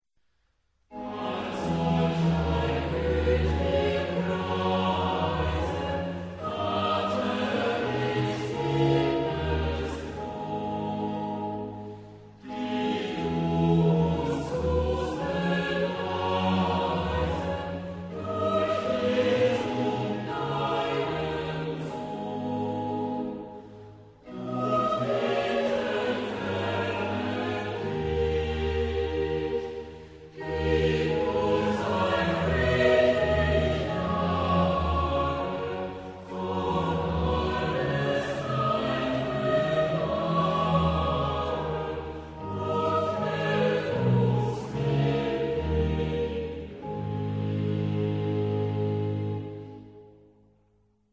Maar er is Maar er is één belangrijke uitzondering: als op een opmaat aan het begin  (van een stuk of van een frase) een Ie trap wordt geplaatst, kan deze Ie trap op de eerste tel worden herhaald.